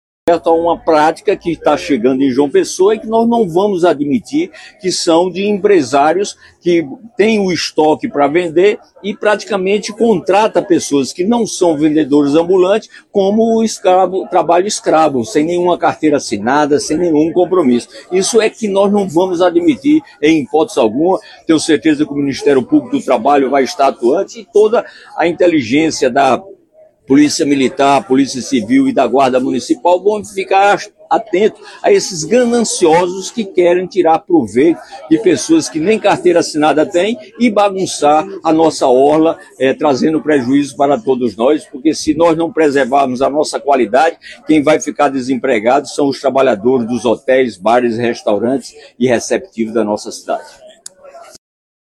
O prefeito Cícero Lucena (Progressistas) fez duras críticas, nesta segunda-feira (2), à prática de exploração de mão de obra disfarçada de comércio ambulante na orla da capital. Durante a entrega da reforma da Unidade de Saúde da Família (USF) Timbó, ele afirmou que não permitirá a ação de empresários que contratam pessoas sem carteira assinada para vender mercadorias no calçadão, caracterizando o que chamou de “trabalho escravo”.